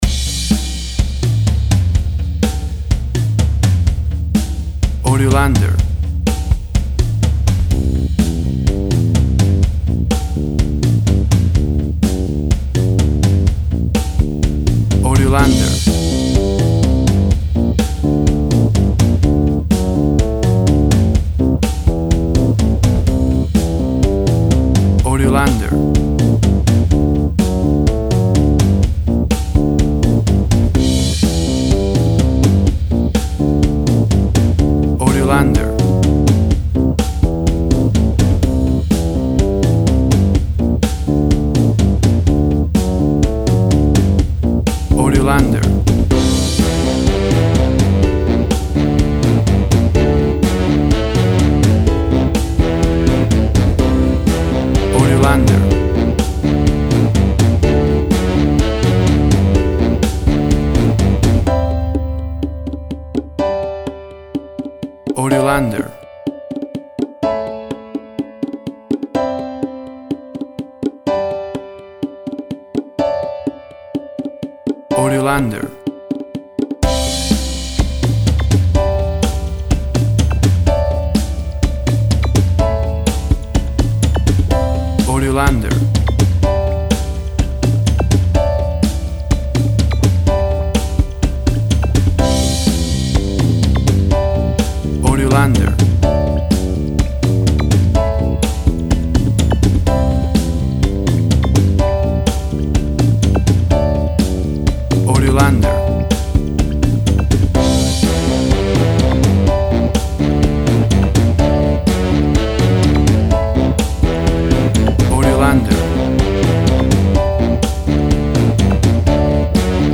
Tempo (BPM) 125